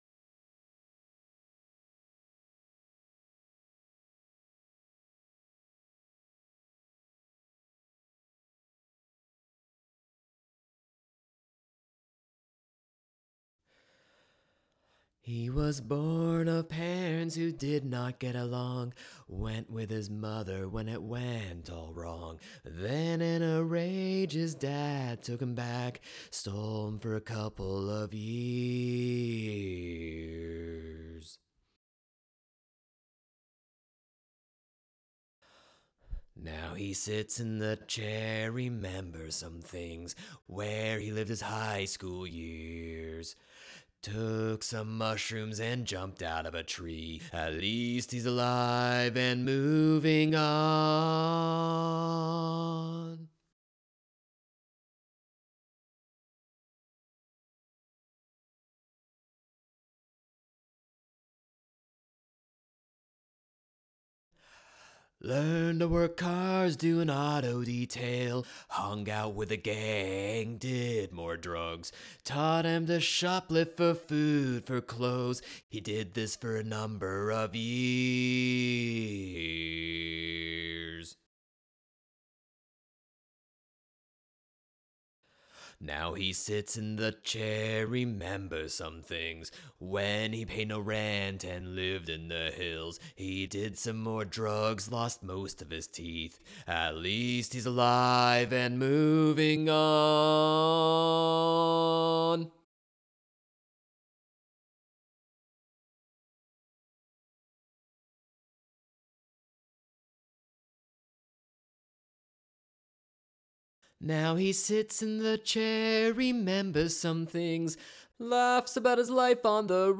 Toward Tomorrow - Vocals - Verse.wav